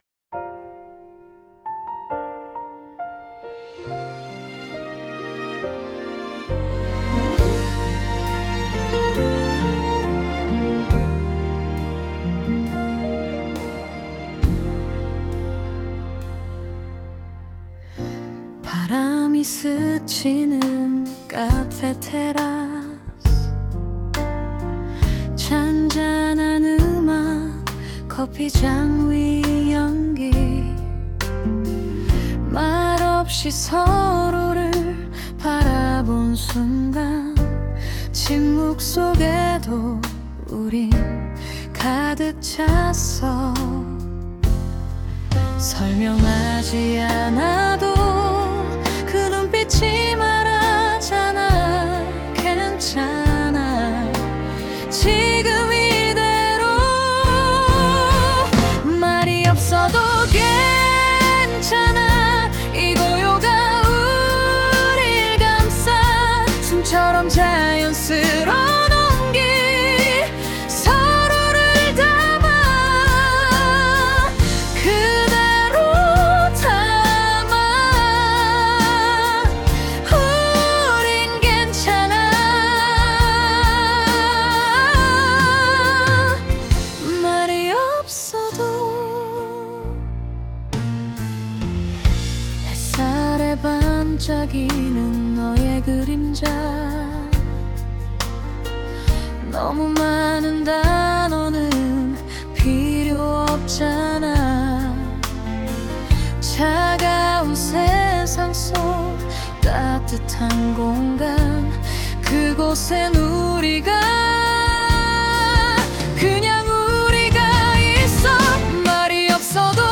생성된 음악
다운로드 설정 정보 Scene (장면) cafe_terrace Topic (주제) 말이 없어도 괜찮은 사이, 침묵 속 안정감, 따뜻한 공기. 설명하지 않아도 이해받고 싶은 마음과, 스스로를 있는 그대로 받아들이는 연습 Suno 생성 가이드 (참고) Style of Music Bossa Nova, Jazz Piano, Lo-Fi, Female Vocals, Soft Voice Lyrics Structure [Meta] Language: Korean Topic: 말이 없어도 괜찮은 사이, 침묵 속 안정감, 따뜻한 공기.